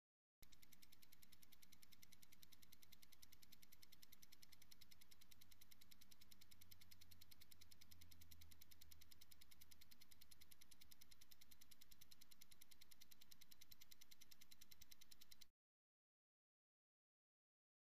Misc. Sports Elements; Fifteen Second Ticking.